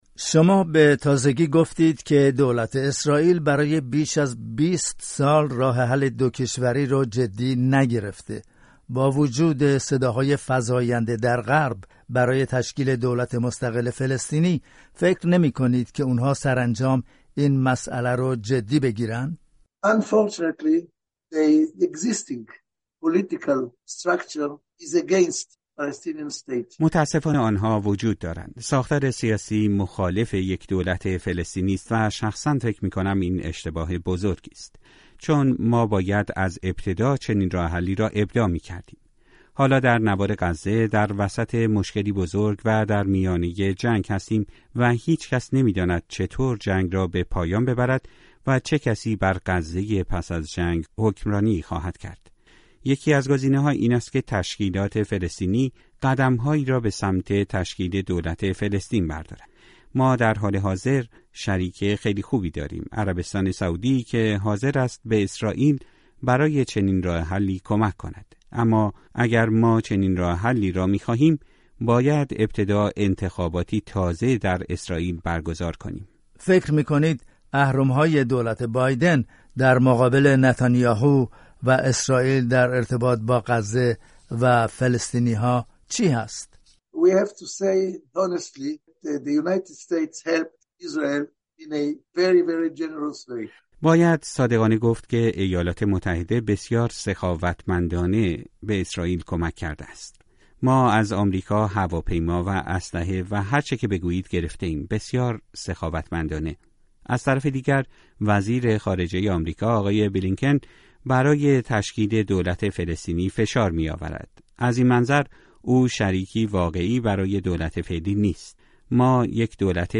گفت‌وگو با یاکوب پری، رئیس پیشین سازمان امنیت داخلی اسرائیل